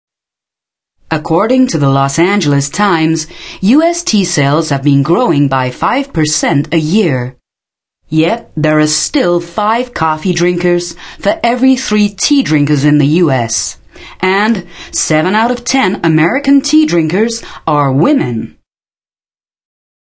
mid-atlantic
Sprechprobe: eLearning (Muttersprache):
female voice over artist german